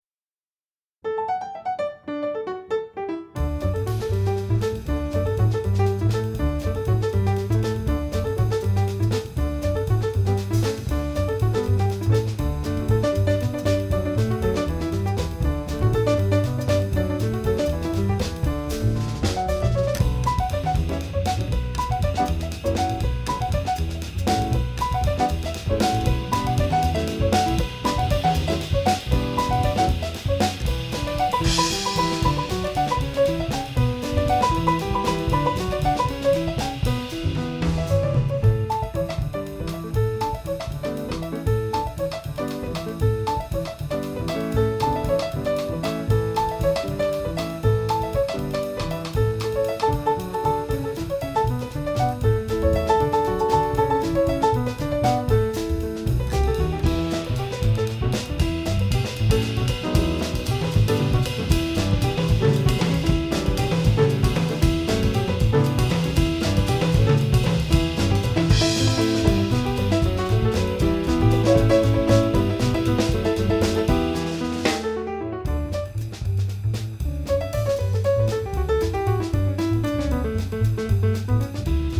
爵士音樂